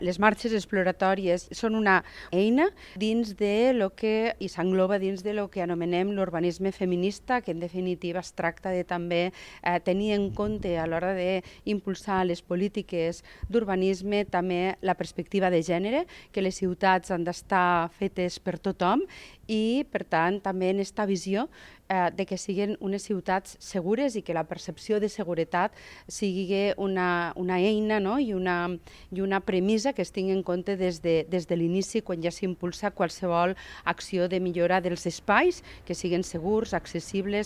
La tinent d’alcalde d’igualtat i feminismes de l’Ajuntament de Tortosa, Mar Lleixà, ha destacat que és una eina per incorporar la perspectiva de gènere en el disseny de les ciutats amb l’objectiu d’incrementar la percepció de seguretat…